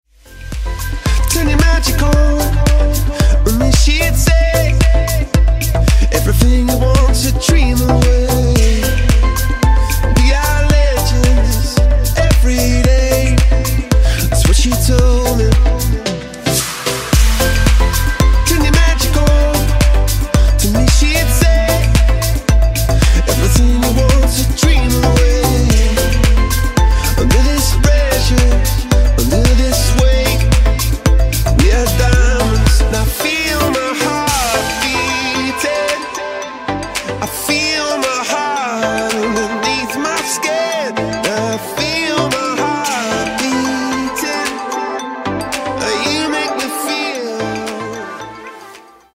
• Качество: 160, Stereo
мужской вокал
club
vocal